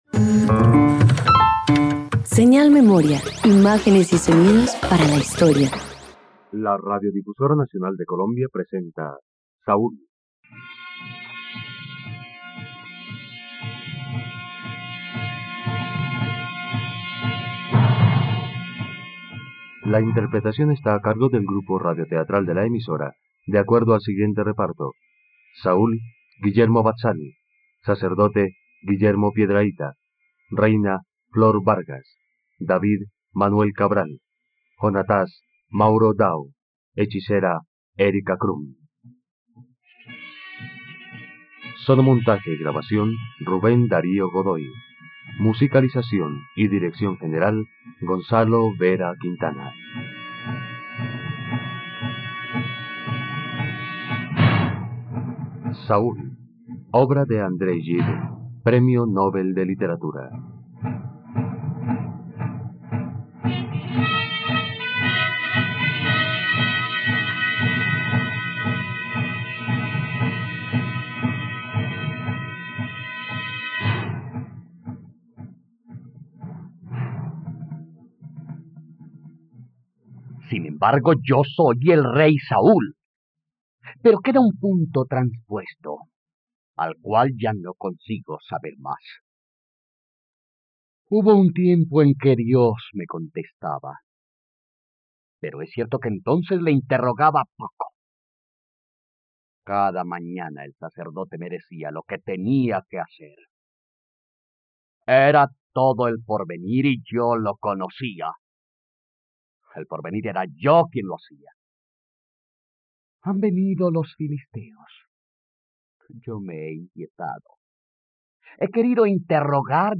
..Radionovela. Escucha ahora la versión radiofónica de Saúl, obra original de André Gide, en los Radioteatros dominicales de la plataforma de streaming RTVCPlay.